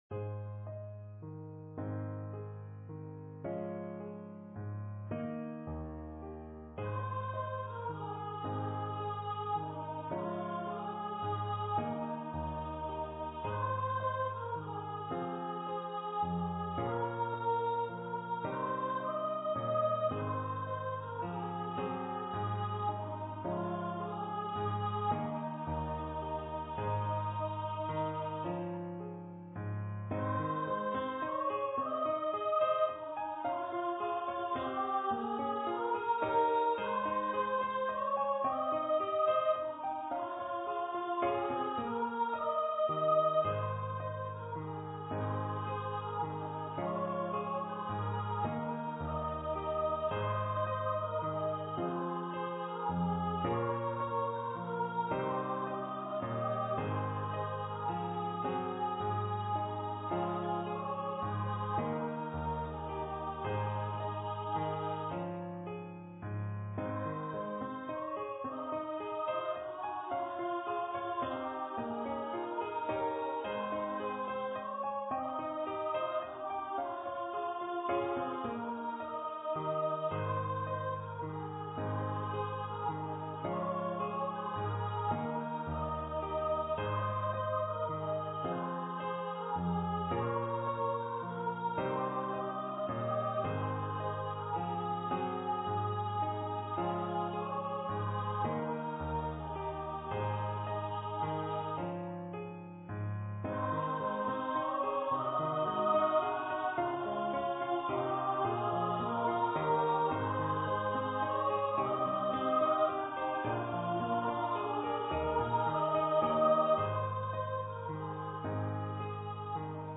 for female voice choir
two-part (SA) choir with soloists and piano accompaniment
Choir - 2 part upper voices